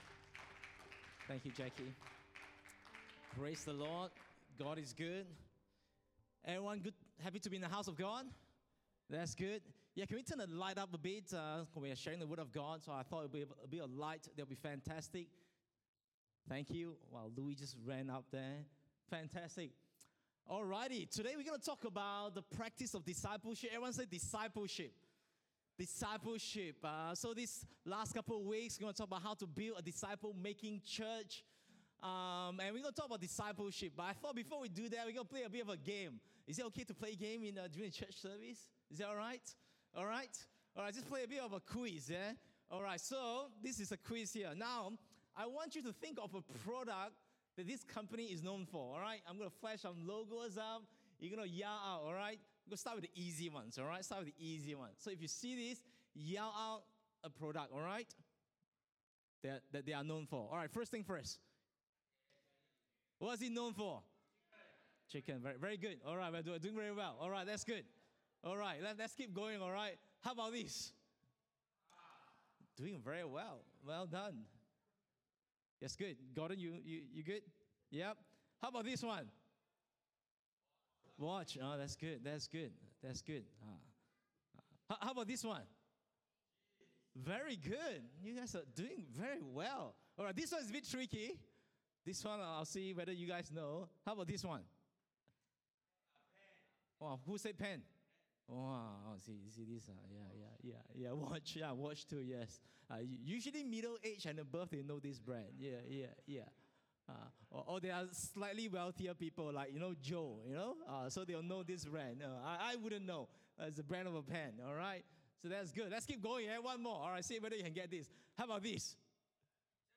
English Worship Service - 31 July 2022